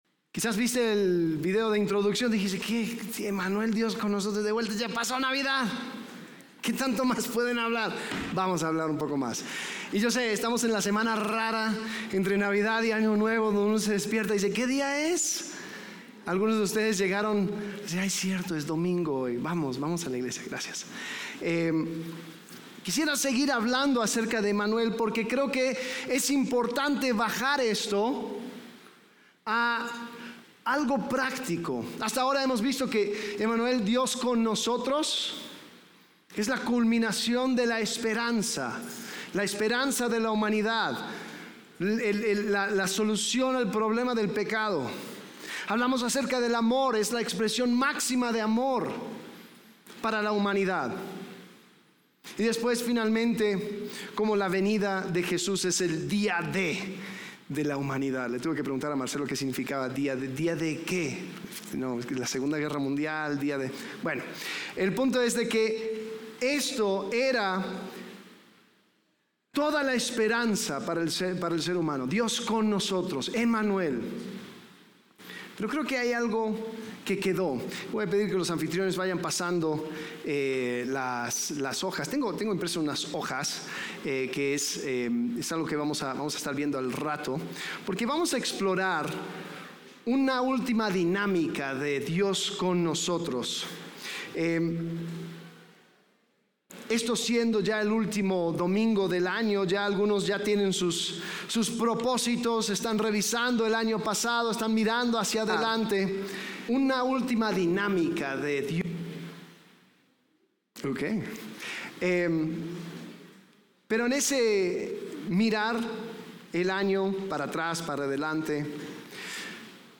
Dios con Nosotros Passage: Romans 8 Servicio: Domingo « Navidad